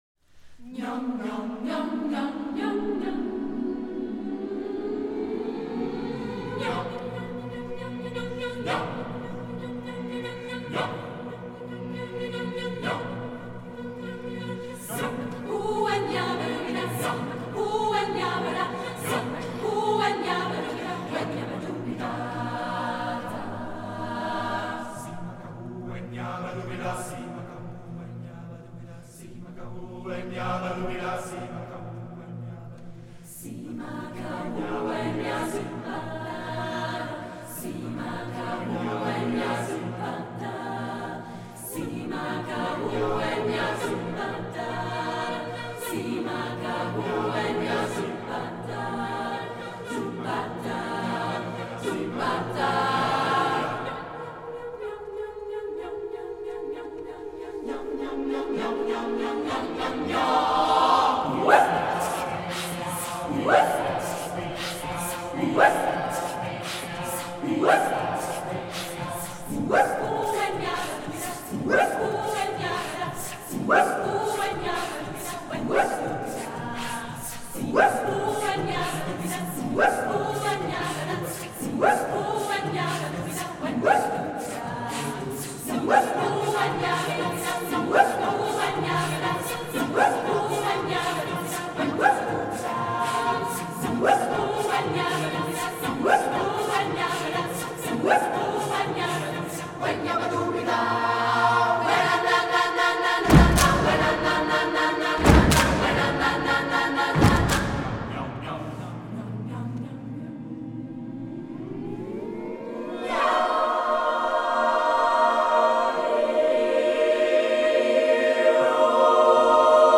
Tëuta su ai 27.04.2025 tla dlieja de Urtijëi